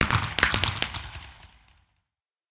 Minecraft Version Minecraft Version 25w18a Latest Release | Latest Snapshot 25w18a / assets / minecraft / sounds / fireworks / twinkle_far1.ogg Compare With Compare With Latest Release | Latest Snapshot
twinkle_far1.ogg